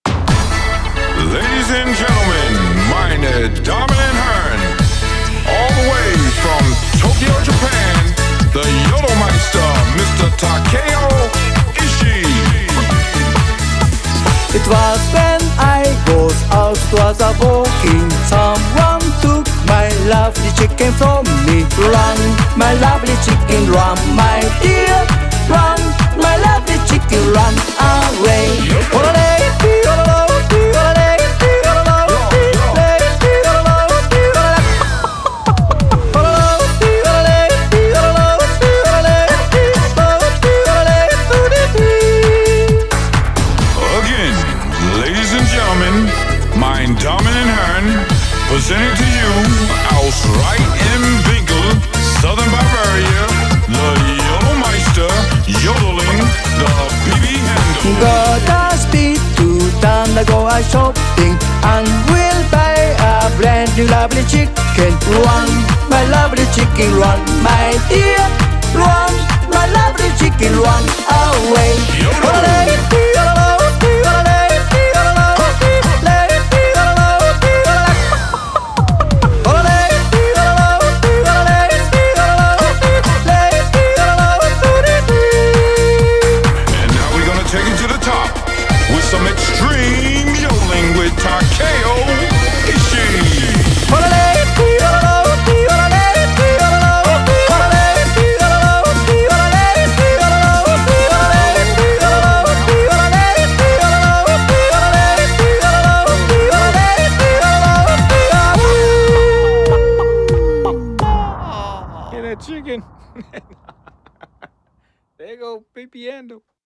BPM133
Audio QualityPerfect (High Quality)
[CHICKEN YODELING]